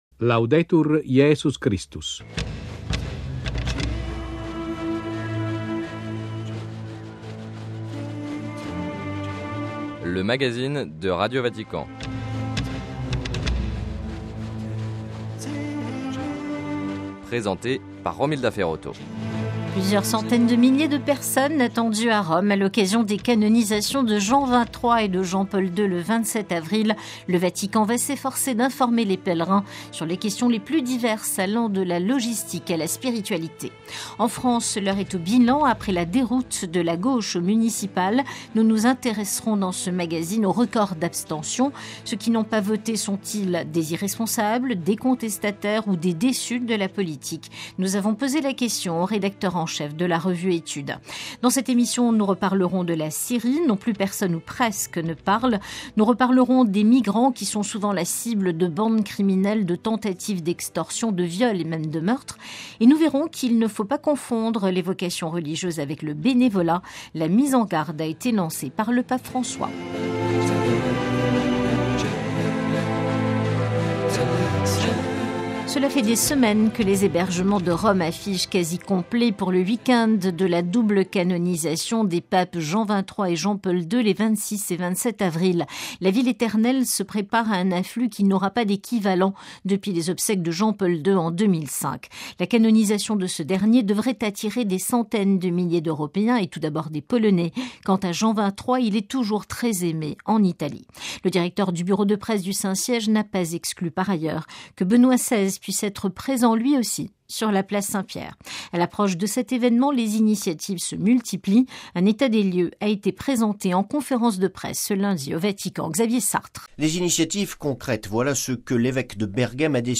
Sommaire : - Rome se prépare à la canonisation de Jean XXIII et de Jean-Paul II. - Entretien